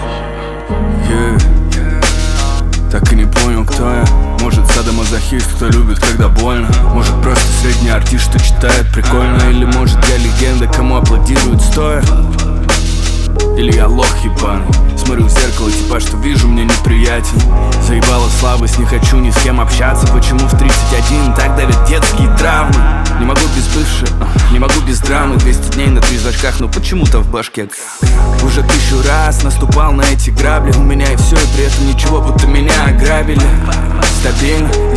Hip-Hop Rap